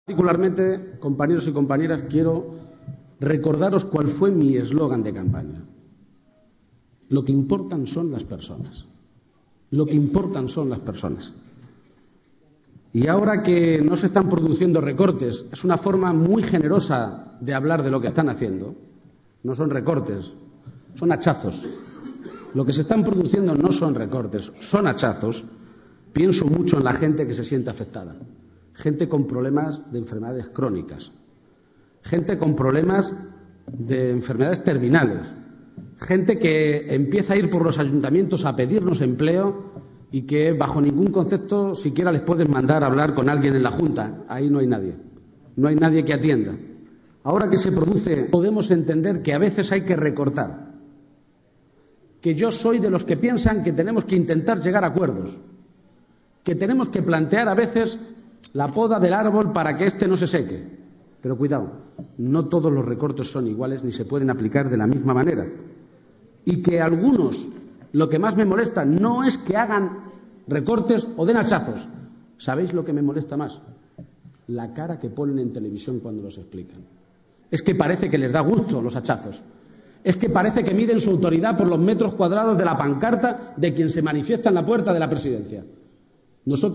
Intervención García-Page